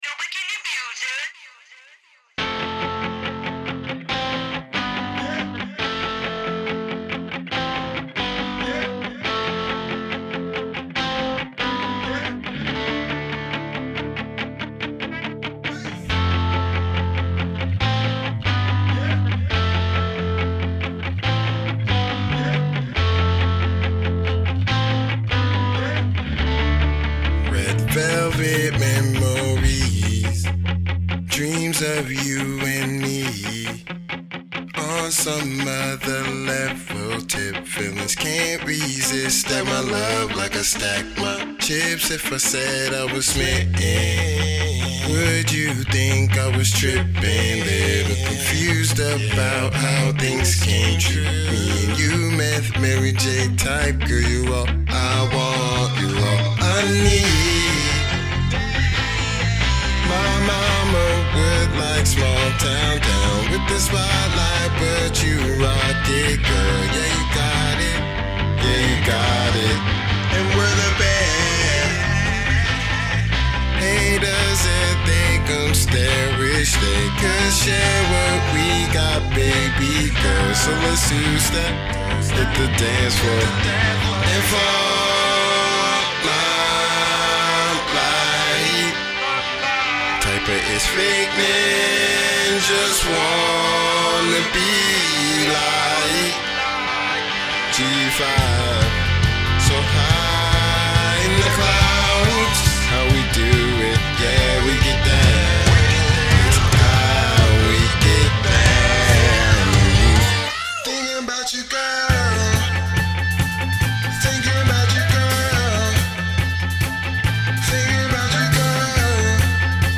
Genre : hip hop / indie / alternative